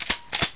銃のリロード
sung_thay_dan.mp3